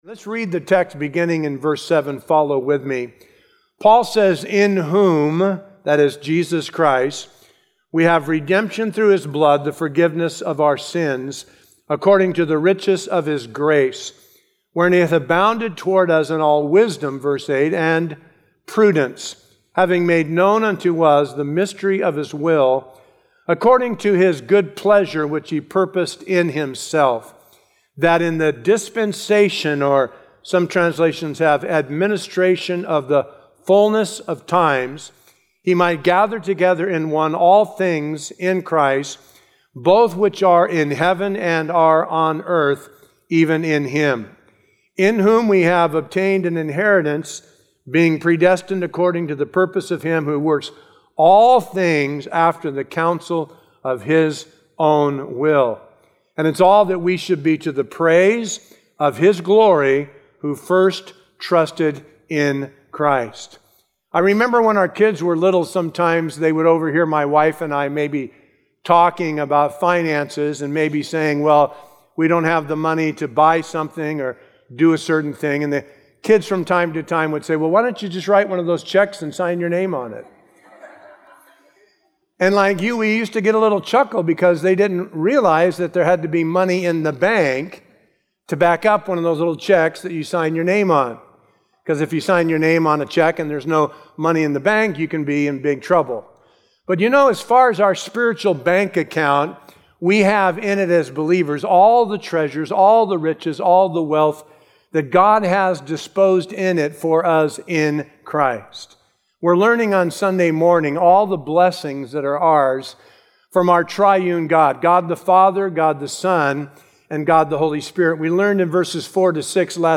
Sermon info